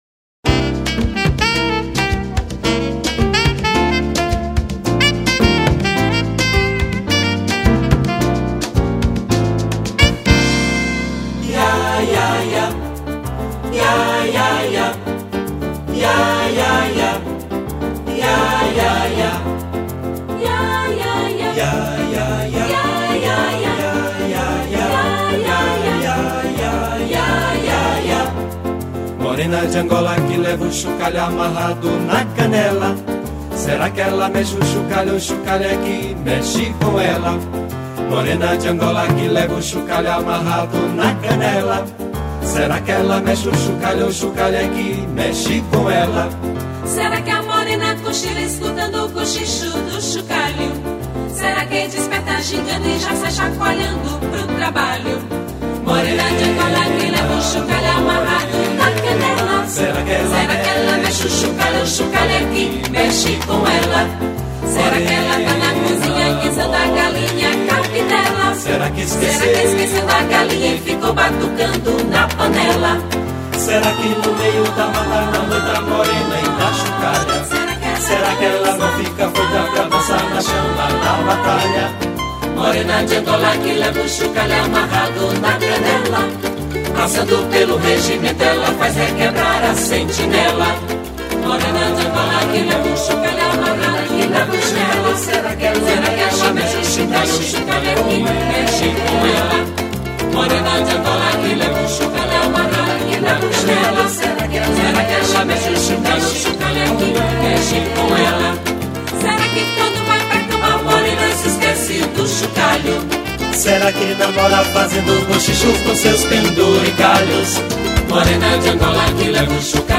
200   03:59:00   Faixa:     Samba